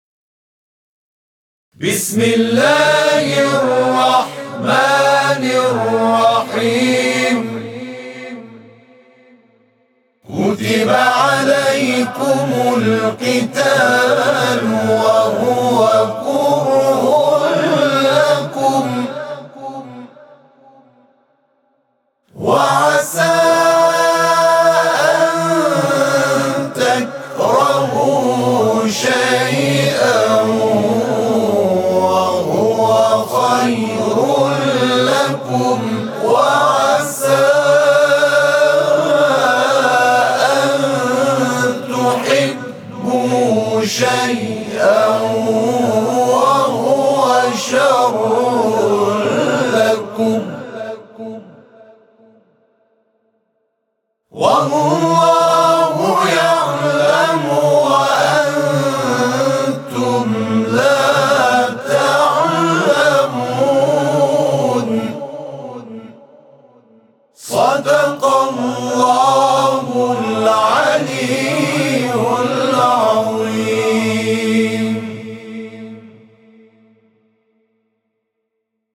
همخوانی آیات 120 و 216 سوره «بقره» را از سوی گروه همخوانی و تواشیح «محمد رسول‌الله(ص)» در راستای نهضت ملی «زندگی با آیه‌ها» می‌شنوید.
صوت همخوانی آیه 216 سوره مبارکه «بقره»/ روز دوم رمضان